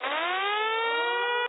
KILL BILL FX 2.wav